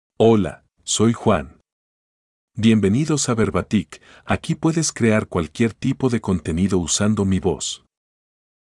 Juan — Male Spanish (Costa Rica) AI Voice | TTS, Voice Cloning & Video | Verbatik AI
MaleSpanish (Costa Rica)
Juan is a male AI voice for Spanish (Costa Rica).
Voice sample
Listen to Juan's male Spanish voice.
Juan delivers clear pronunciation with authentic Costa Rica Spanish intonation, making your content sound professionally produced.